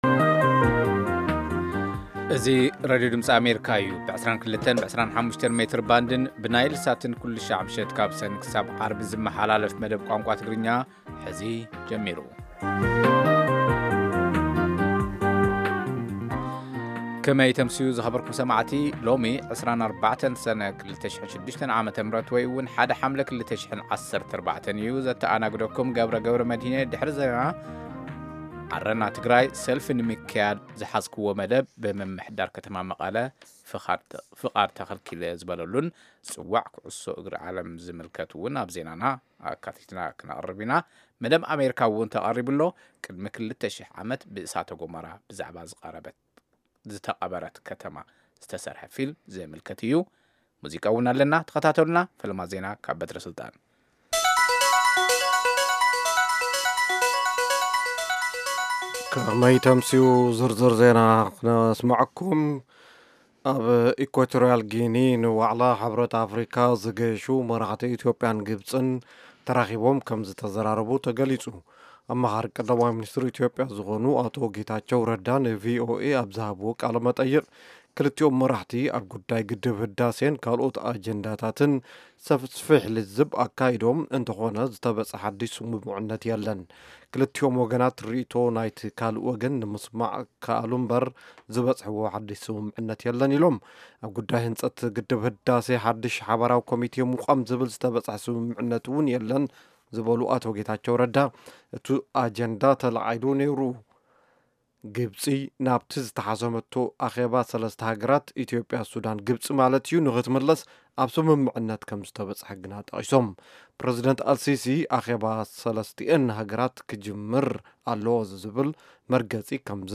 Half-hour broadcasts in Tigrigna of news, interviews with newsmakers, features about culture, health, youth, politics, agriculture, development and sports on Monday through Friday evenings at 10:00 in Ethiopia and Eritrea.